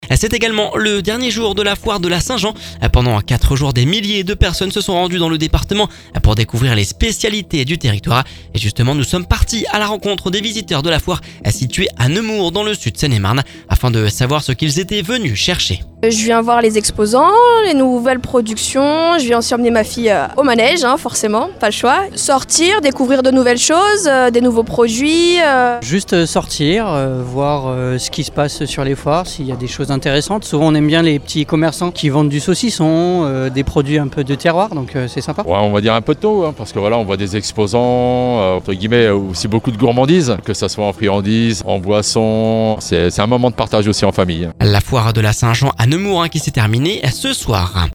Pendant 4 jours, des milliers de personnes se sont rendus dans le département pour découvrir les spécialités du territoire. Nous sommes partis à la rencontre des visiteurs de la foire située à Nemours dans le sud Seine-et-Marne afin de savoir ce qu’ils étaient venu chercher !